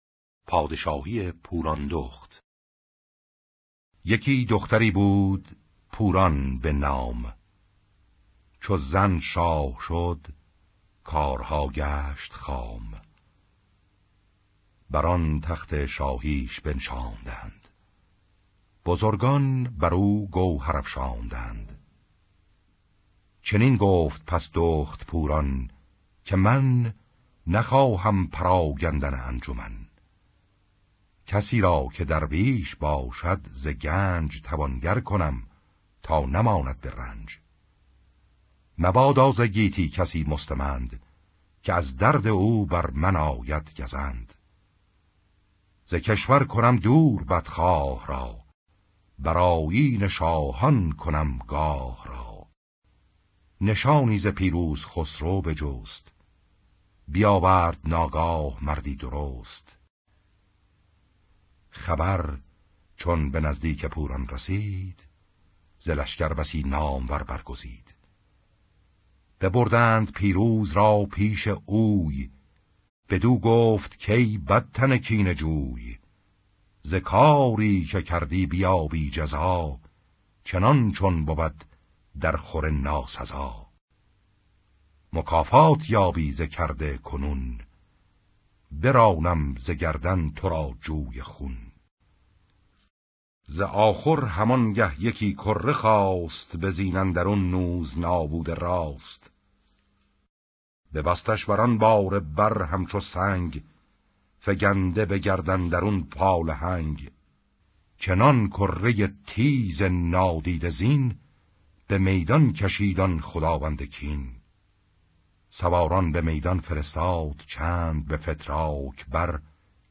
پوراندخت پادشاهی پوراندخت شاهنامه شاهنامه صوتی فردوسی پوراندخت ۱ ۰ نظرات (۰) هیچ نظری هنوز ثبت نشده است ارسال نظر آزاد است، اما اگر قبلا در بیان ثبت نام کرده اید می توانید ابتدا وارد شوید .